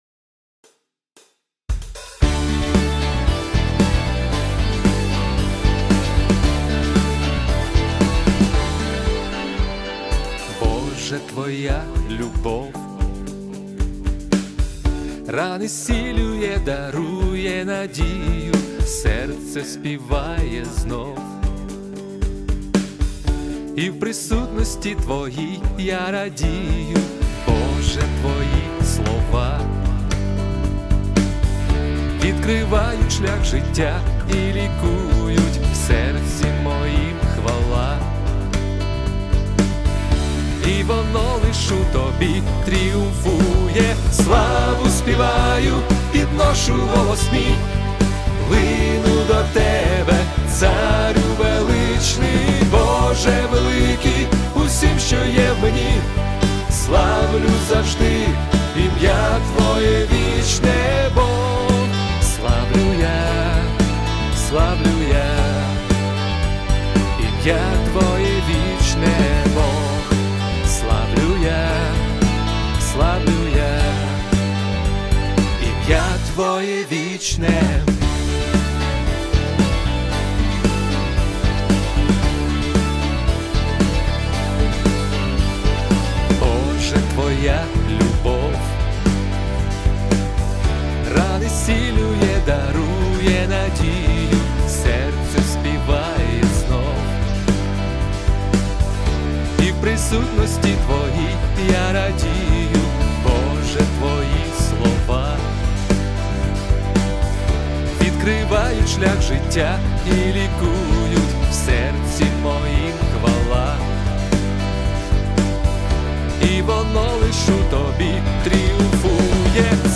песня
104 просмотра 124 прослушивания 6 скачиваний BPM: 114